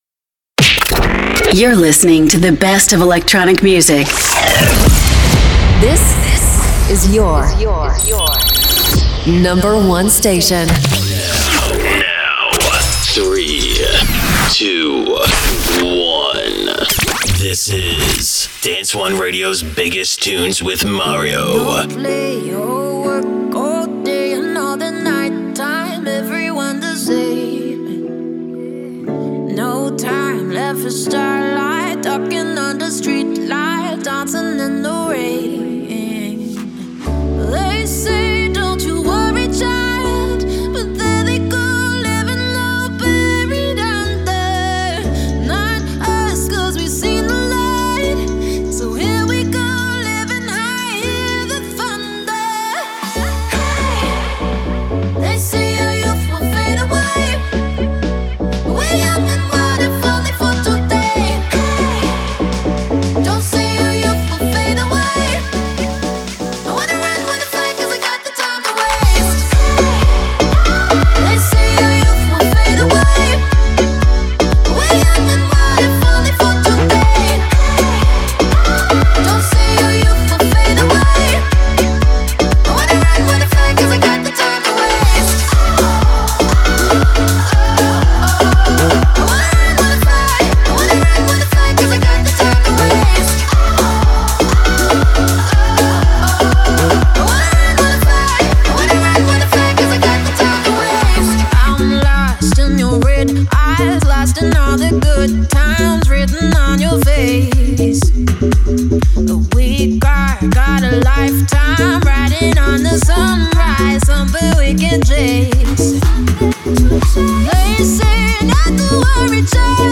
Dance weapons that rule the dance and electronic scene